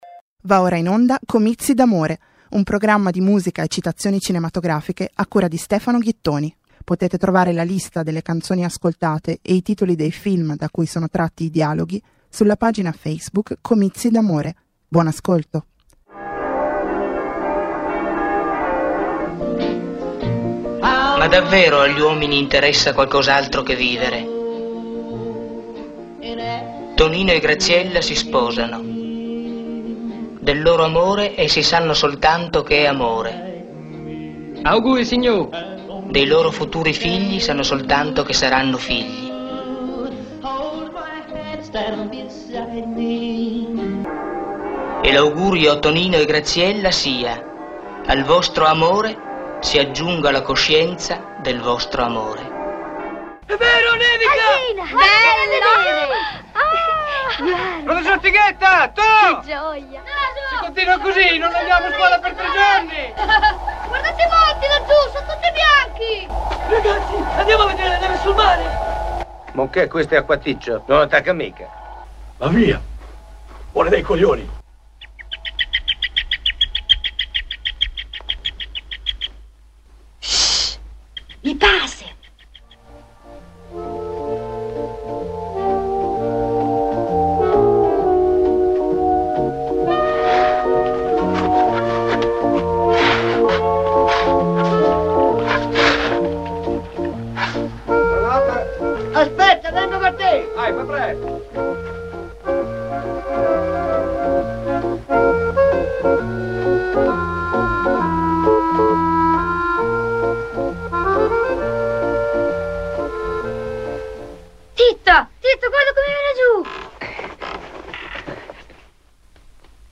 Quaranta minuti di musica e dialoghi cinematografici trasposti, isolati, destrutturati per creare nuove forme emotive di ascolto.